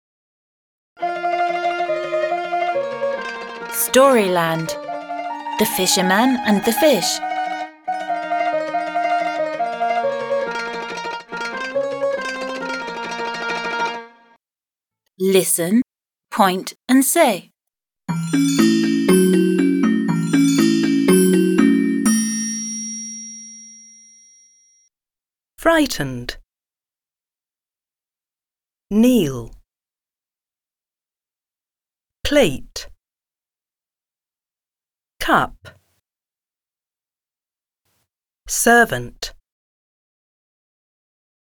07-Vocabulary-p.-46.mp3